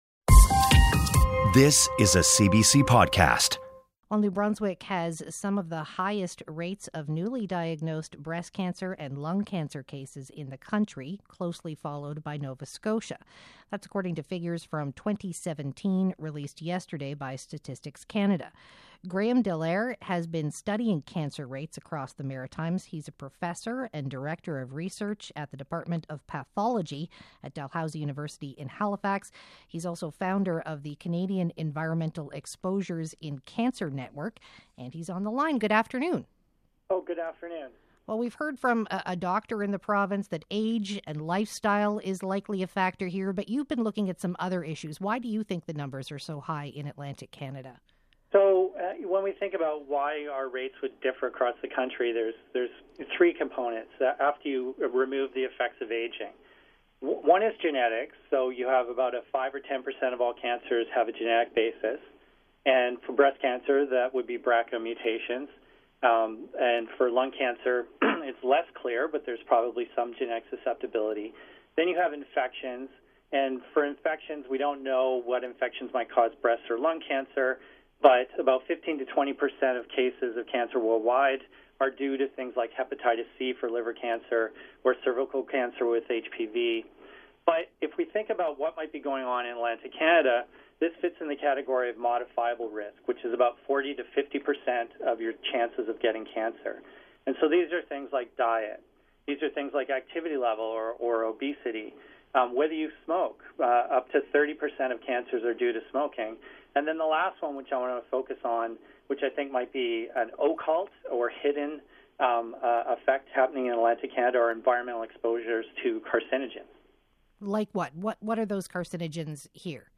Radio interview with CBC on Radon and Arsenic